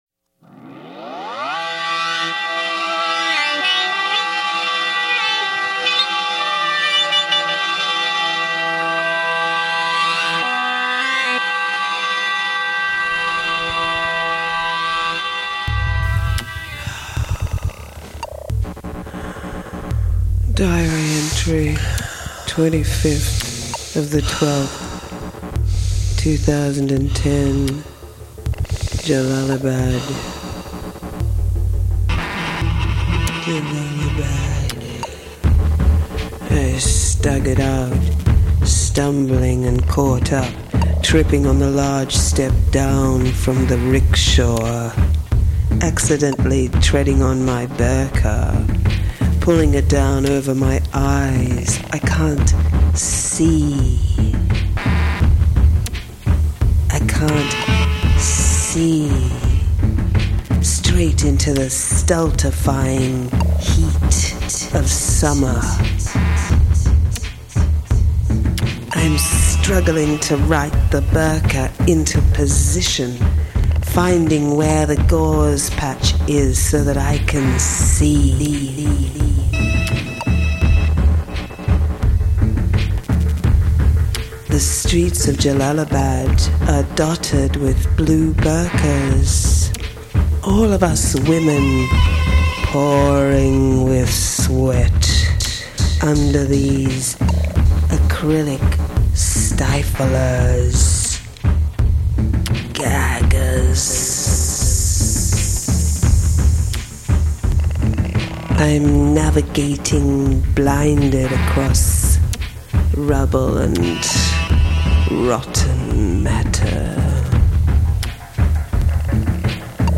Musical accompaniment